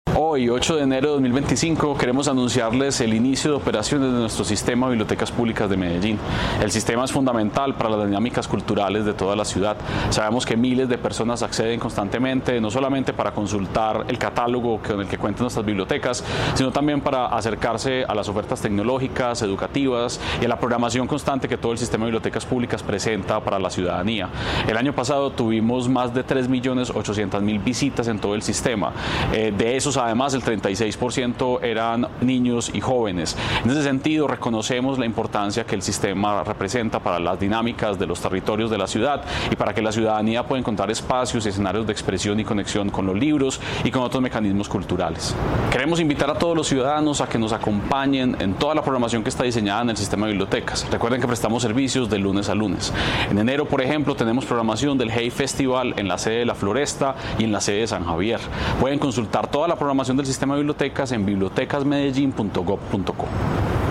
Palabras de Santiago Silva, secretario de Cultura Ciudadana La Alcaldía de Medellín inicia la reapertura del Sistema de Bibliotecas Públicas este miércoles, 8 de enero, para que la comunidad disfrute y utilice estos espacios.